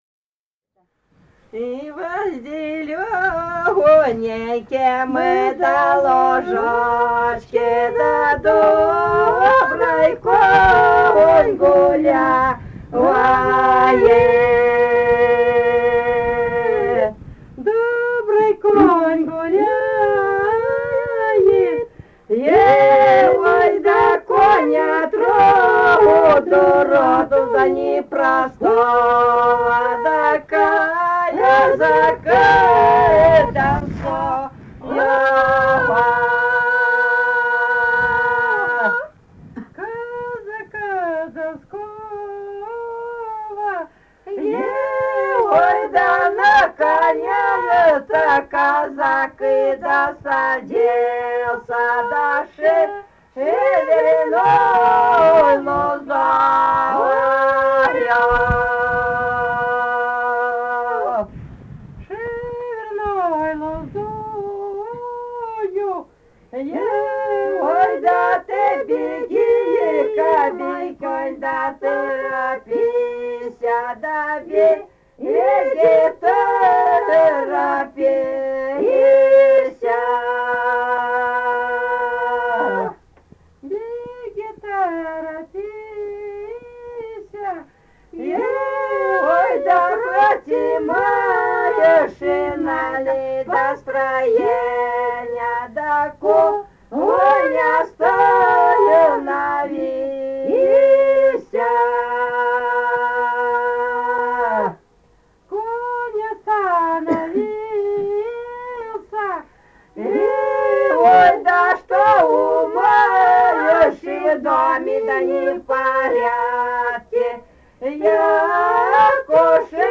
полевые материалы
«Во зелёненьком лужочке» (лирическая «казачья»).
Бурятия, с. Петропавловка Джидинского района, 1966 г. И0903-01